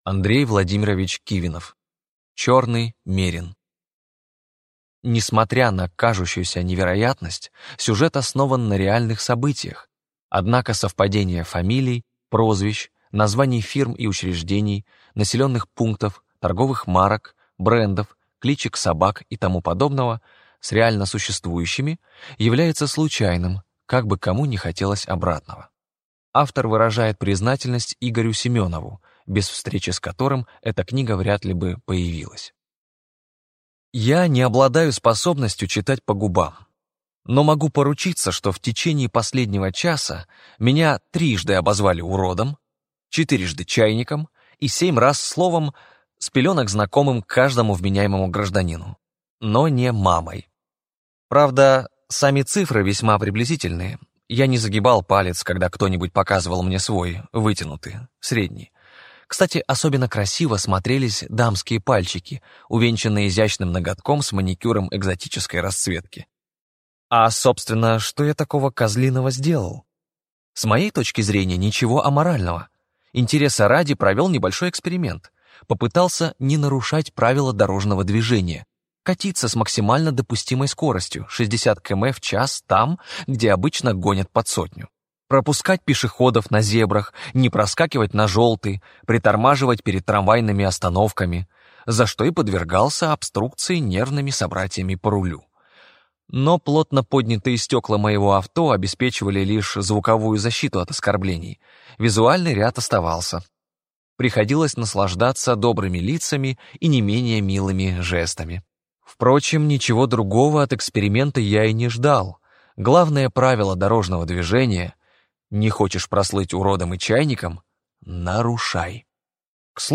Аудиокнига Черный мерин | Библиотека аудиокниг